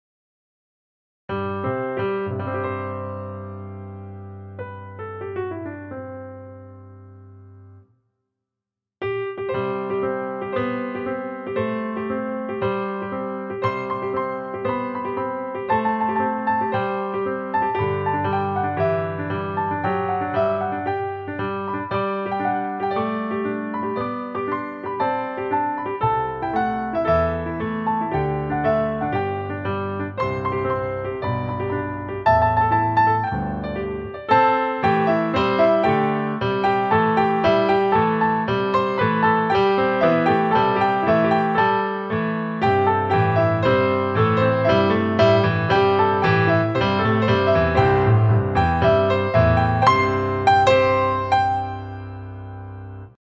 торжественную мелодию
на фортепиано